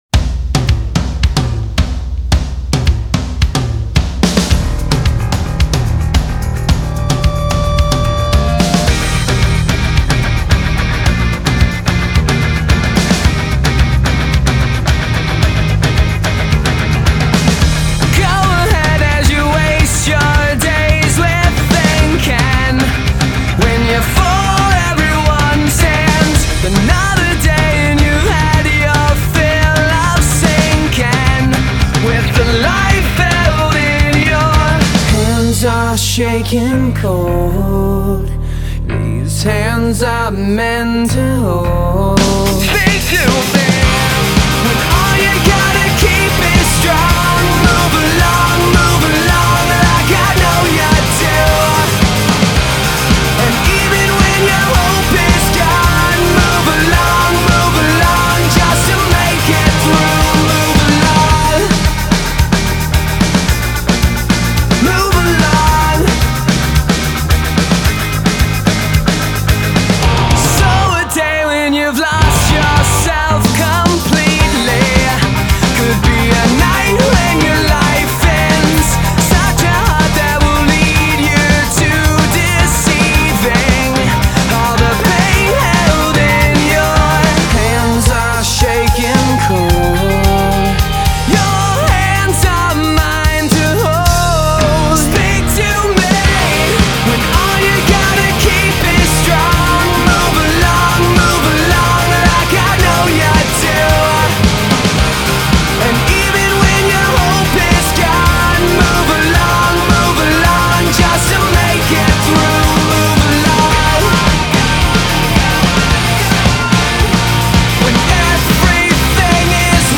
Тема: поп-рок